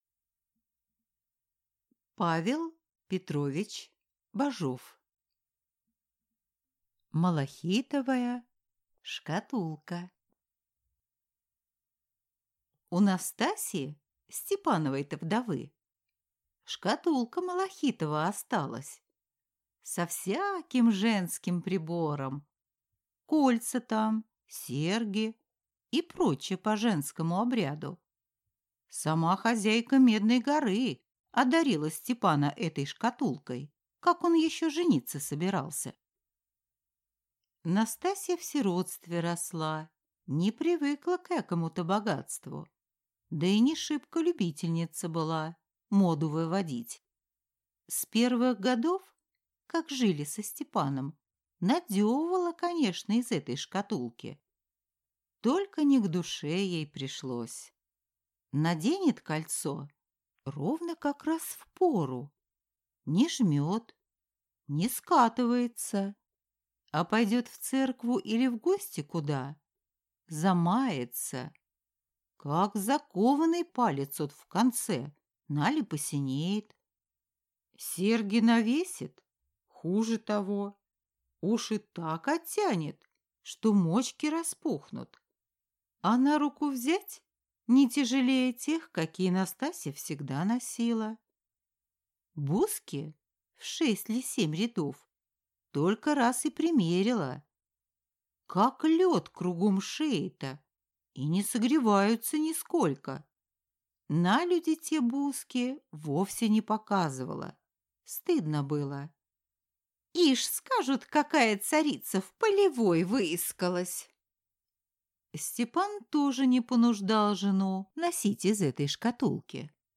Аудиокнига Малахитовая шкатулка | Библиотека аудиокниг